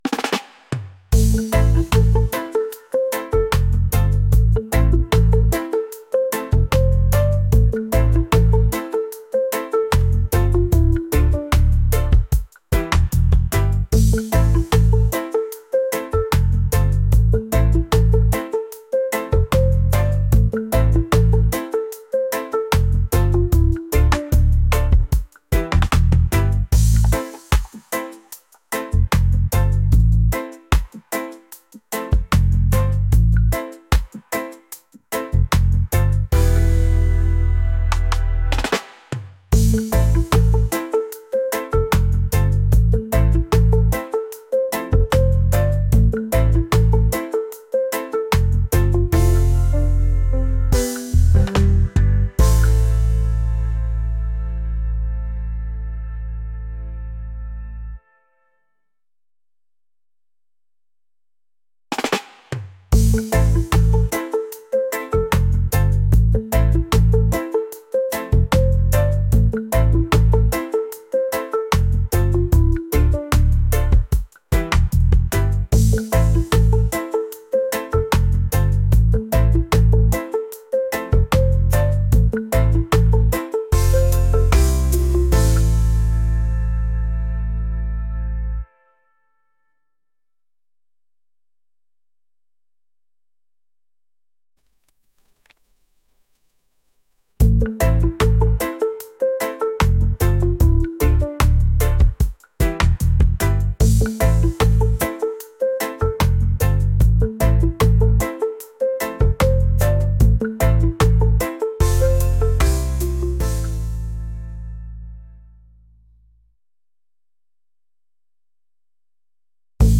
reggae | laid-back | island